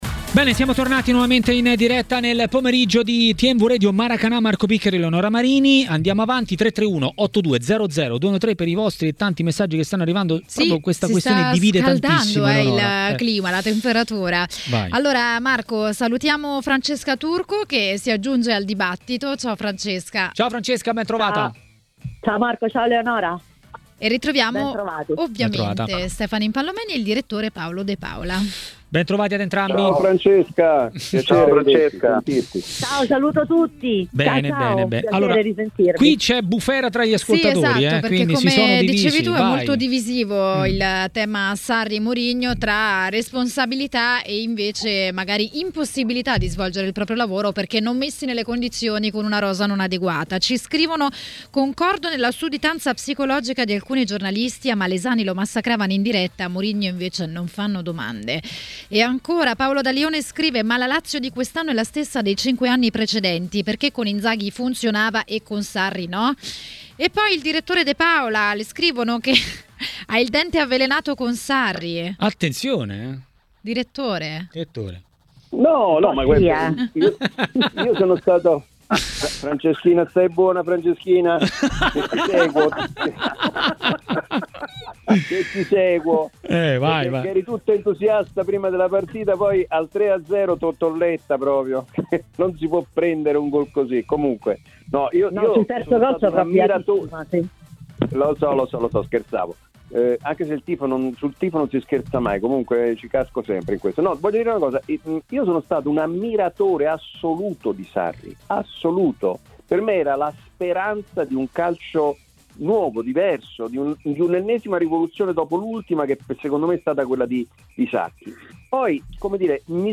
© registrazione di TMW Radio